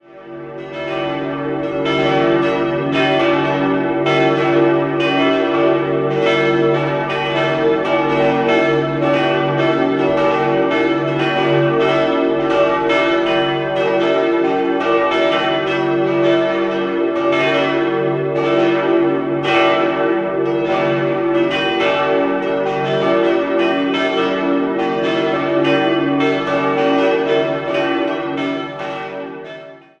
4-stimmiges Geläute: d'-e'-g'-h' D ie drei größeren Glocken wurden 1953 von Karl Czudnochowsky in Erding gegossen, die kleine stammt noch aus dem Vorgängergeläut von 1927 und wurde von den Gebrüdern Klaus in Heidingsfeld gegossen.